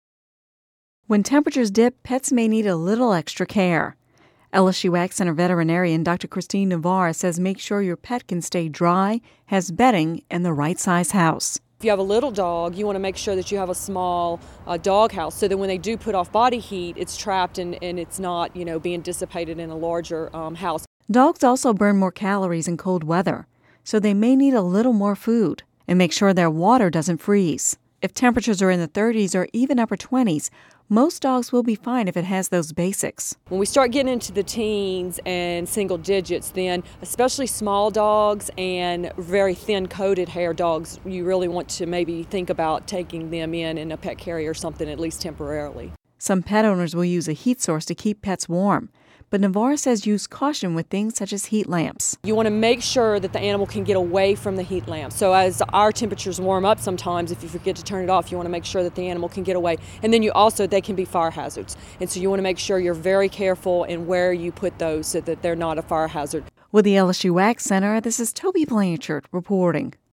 (Radio News 01/17/11) When temperatures dip, pets may need a little extra care.